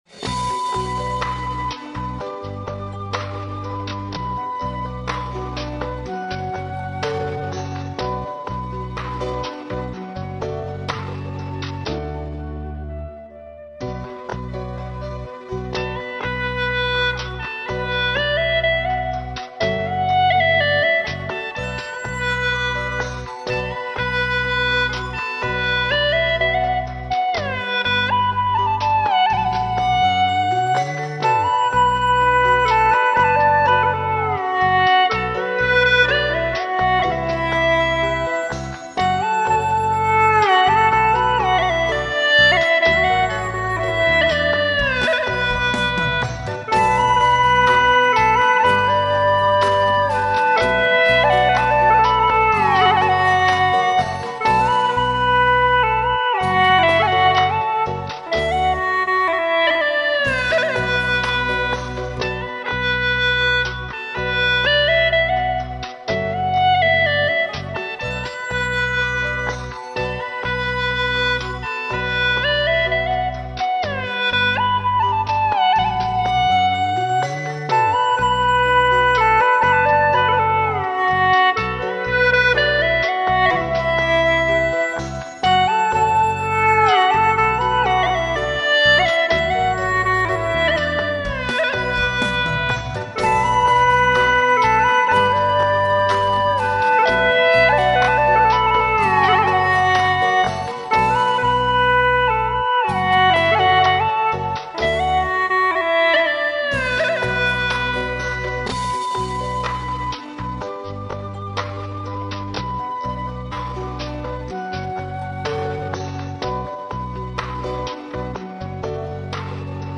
调式 : D 曲类 : 流行